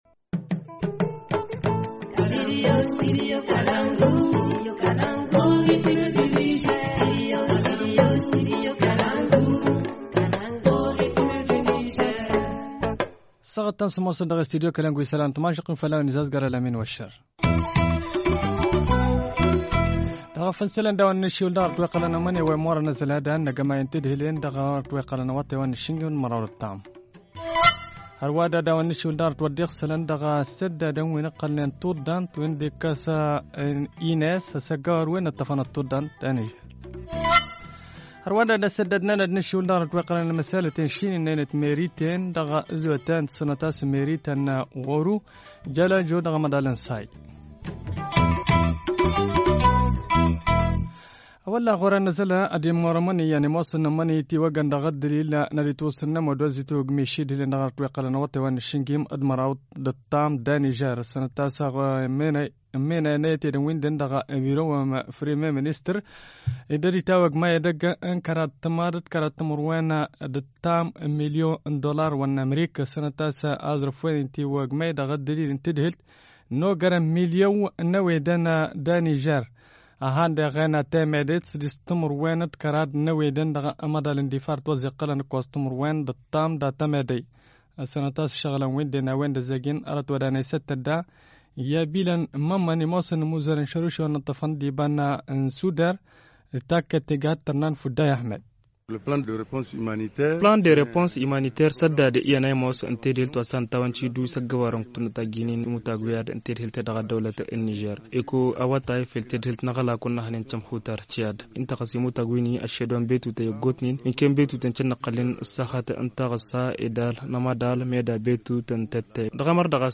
Journal en français